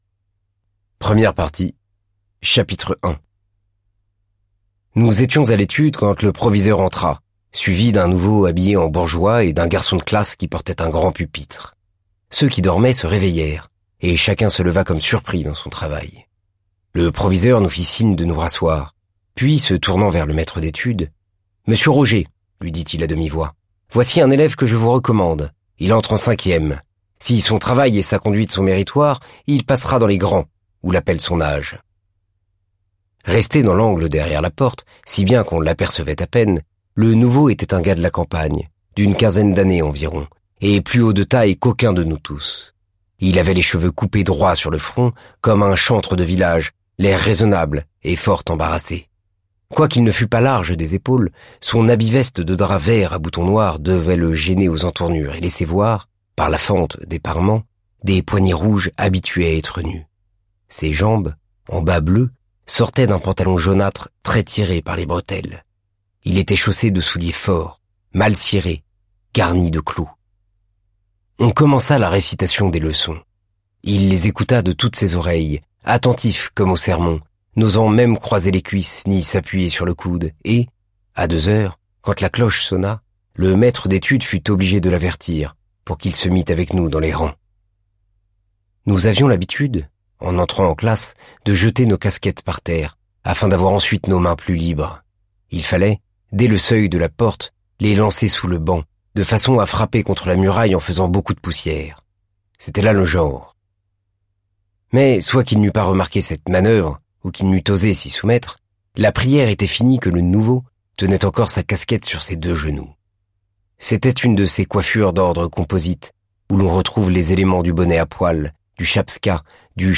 0% Extrait gratuit Madame Bovary de Gustave Flaubert Éditeur : Lizzie Paru le : 2023-08-17 Le chef d'œuvre de Flaubert lu par Félix Moati. Depuis cent cinquante ans, cette pauvre Emma Bovary souffre et pleure dans cent, dans mille villages et villes de France.